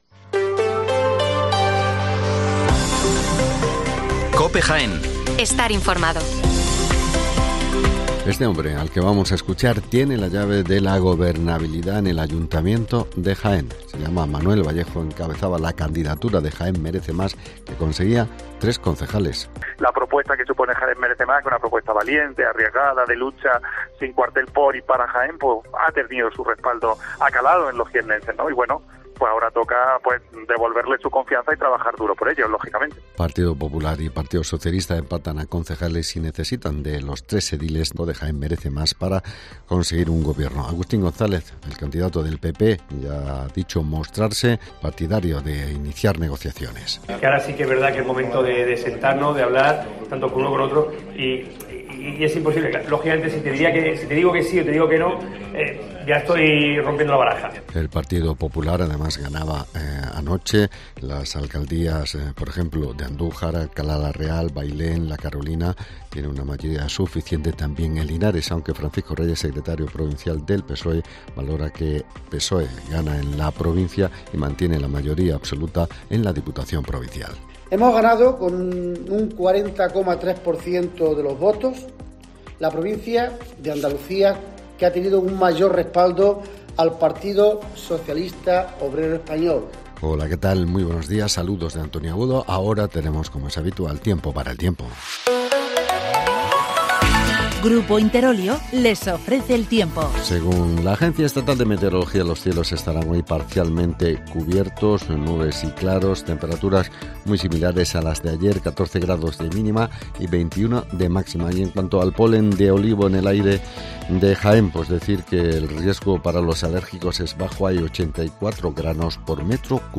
Las noticias locales de las 8'24 del 29 de mayo